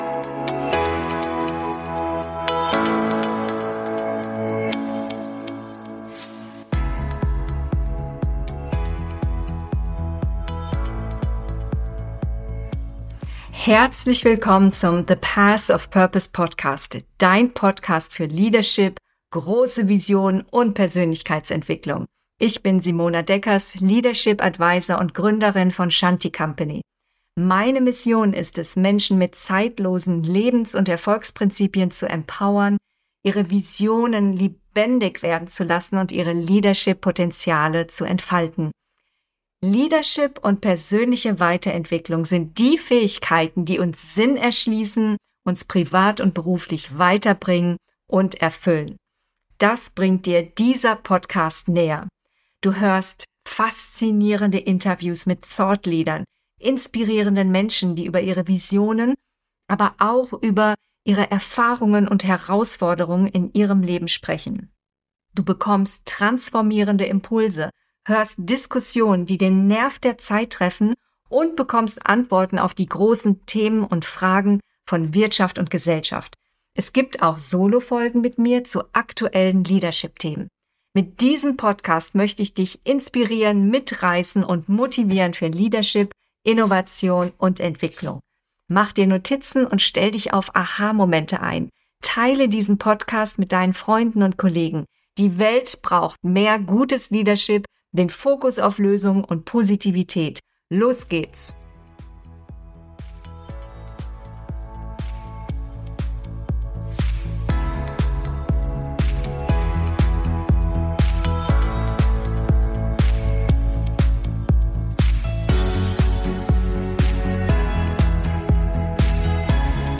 Gerechtigkeit durch Vielfalt - Interview